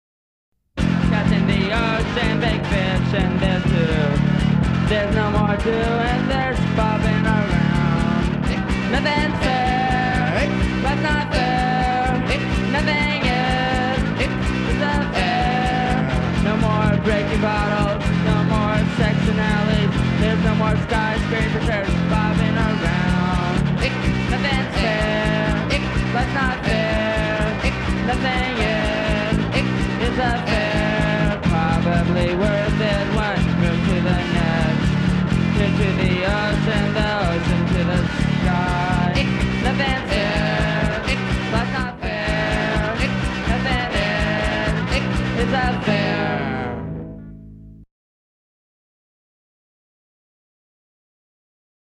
I think we were Lo-Fi before it existed.